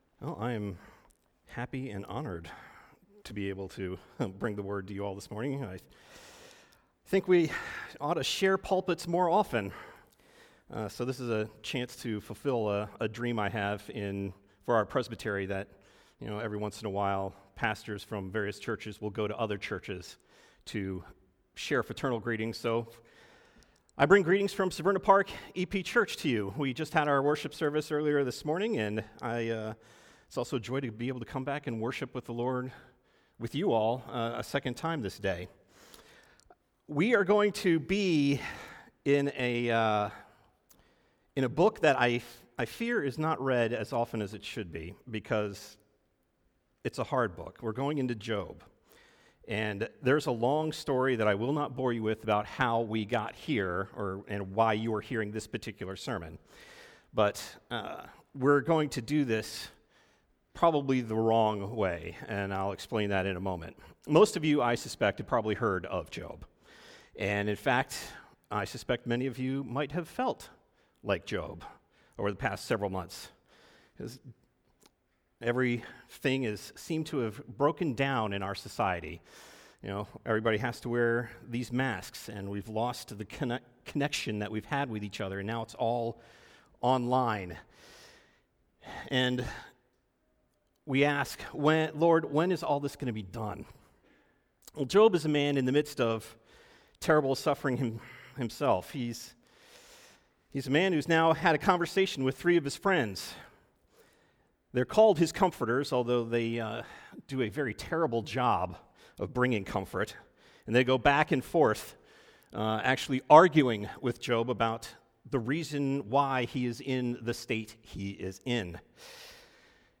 Sermons – Trinity Presbyterian Church
From Series: "Guest Sermons"